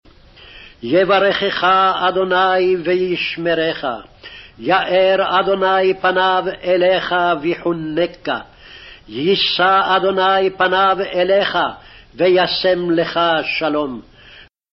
Original Speed |